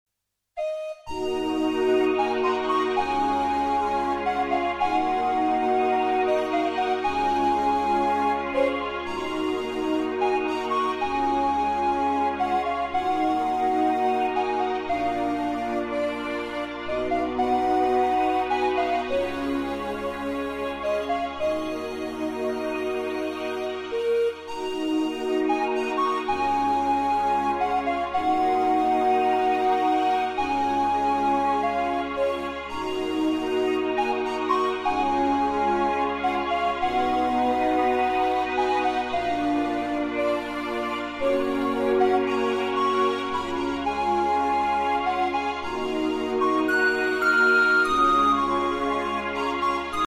Musique Celtique: